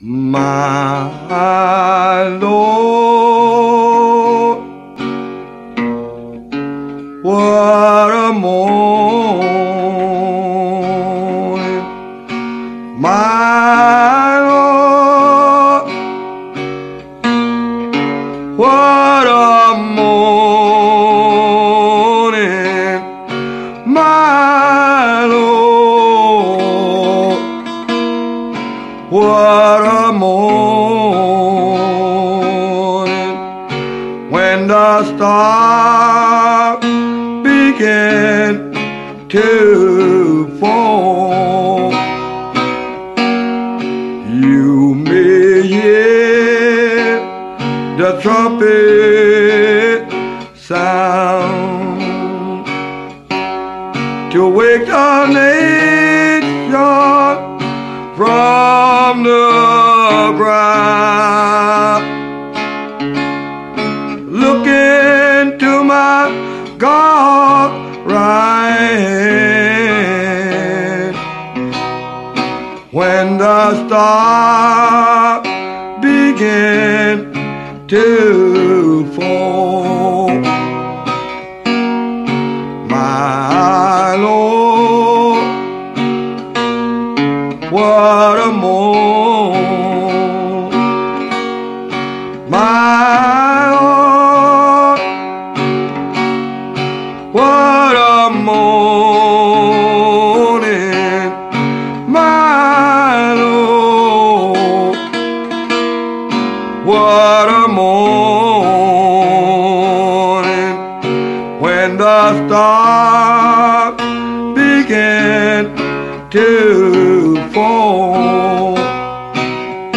he played a kind of blues gospel